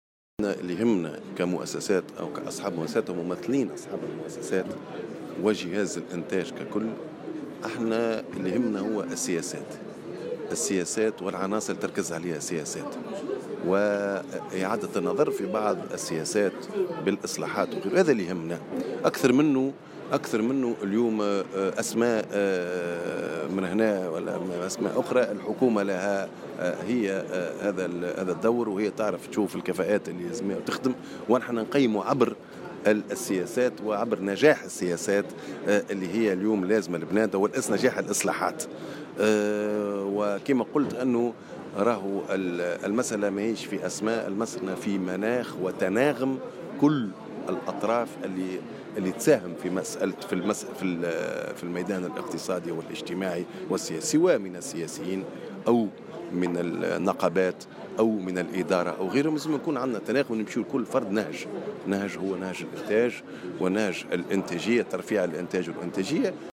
وأضاف في تصريح اليوم لمراسل "الجوهرة أف أم" على هامش الاحتفال باليوم العالمي للشغل بمدينة الثقافة، أن المسألة لا تقتصر على تغيير الأسماء، بل إعادة النظر في بعض السياسات ونجاح الاصلاحات.